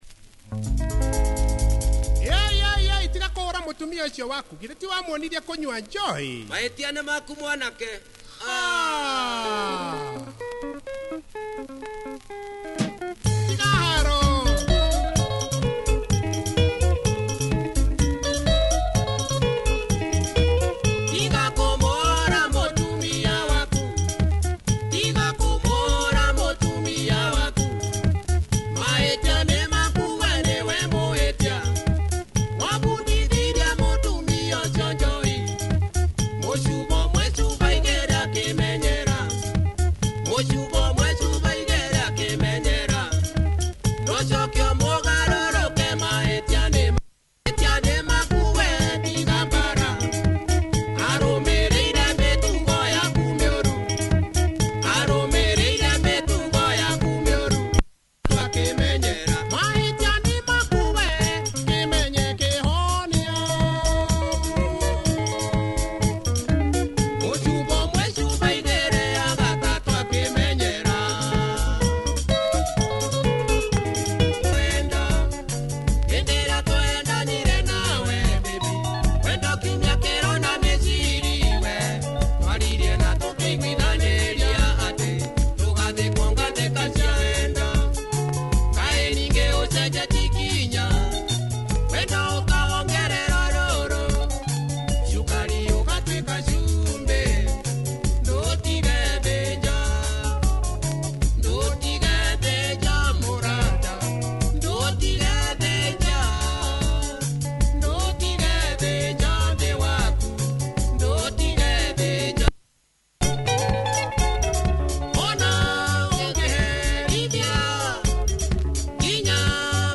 Nice Kikuyu Benga